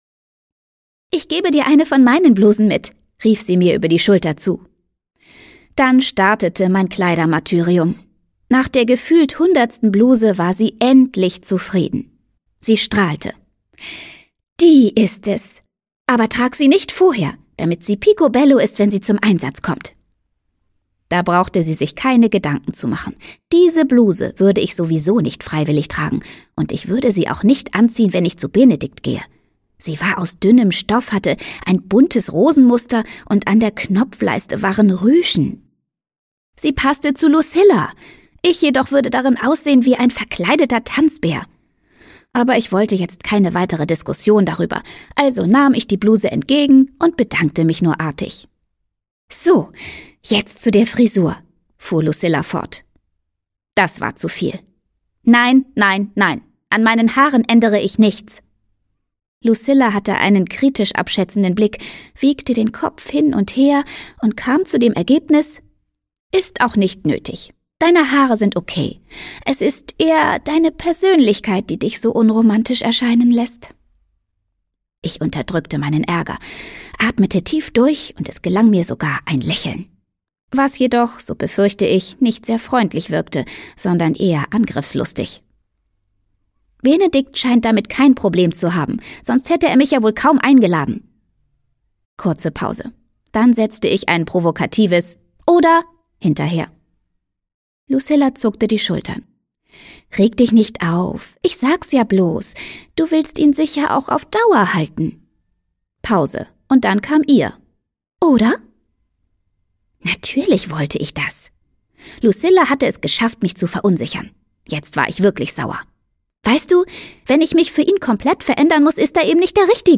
Freche Mädchen: Chaos à la carte - Hortense Ullrich - Hörbuch